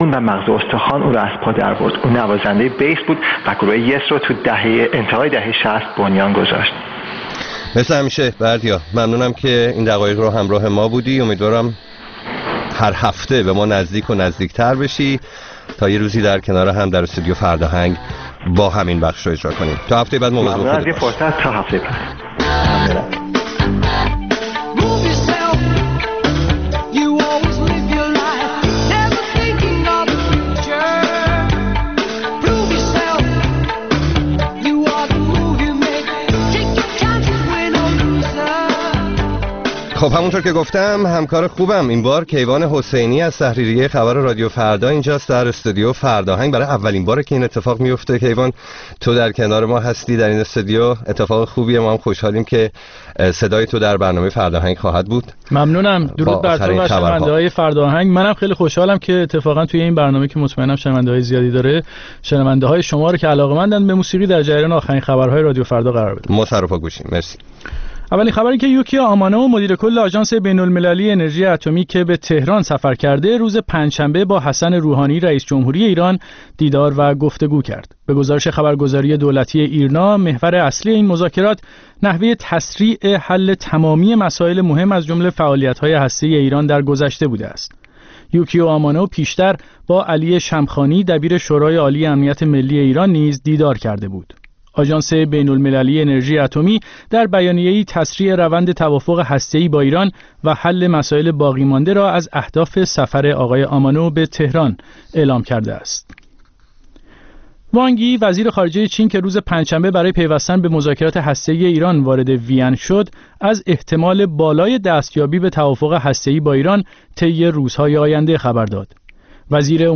برنامه زنده بخش موسیقی رادیو فردا.